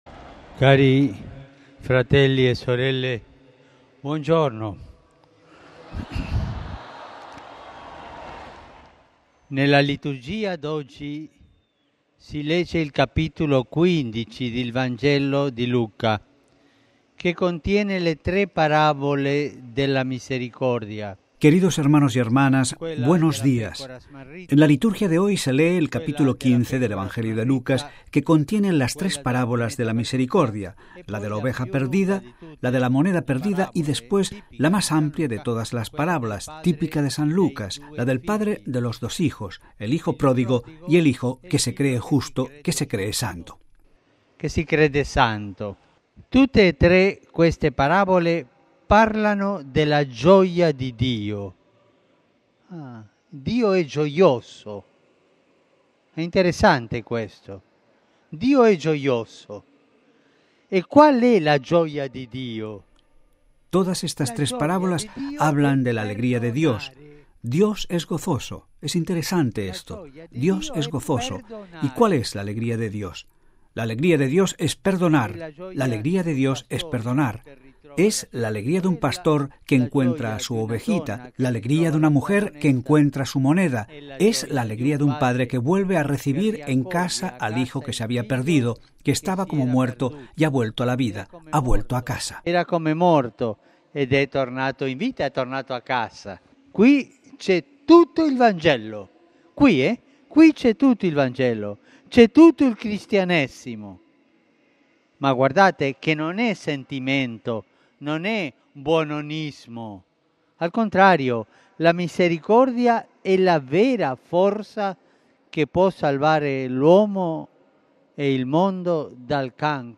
Texto completo de la alocución del Papa Francisco antes de rezar el ángelus: